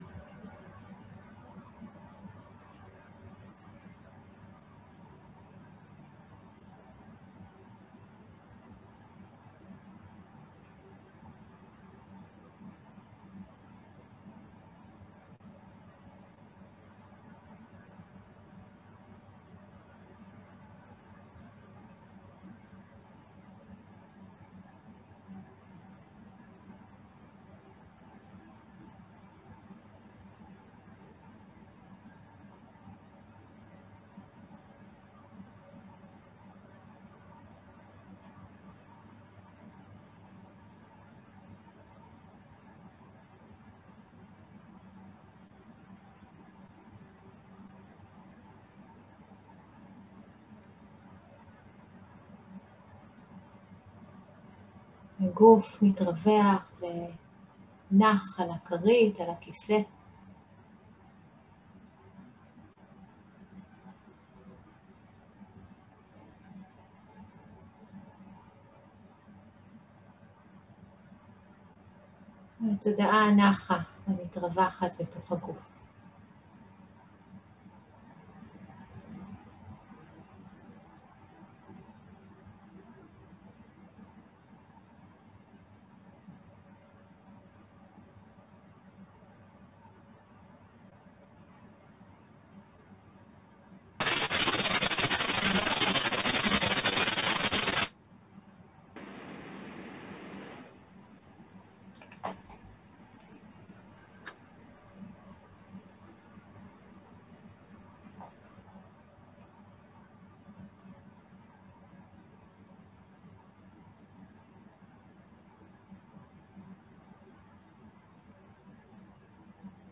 מדיטציה מונחית
Dharma type: Guided meditation שפת ההקלטה